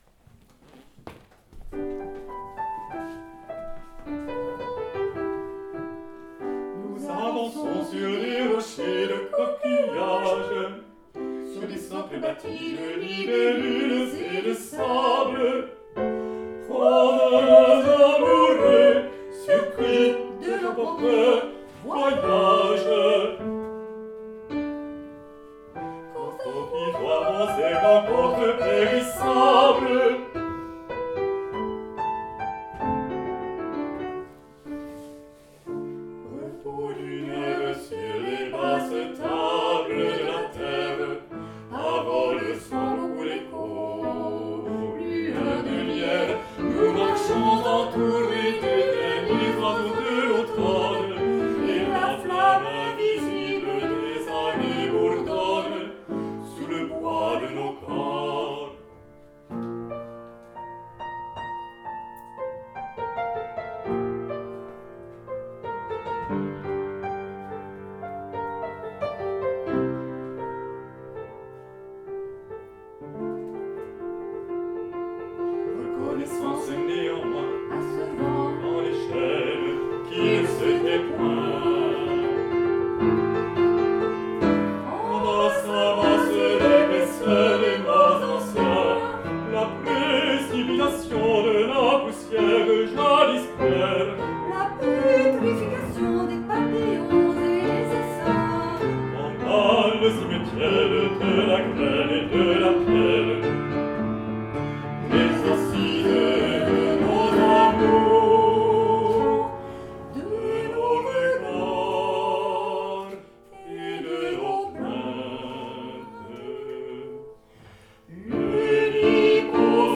Soprano
Baryton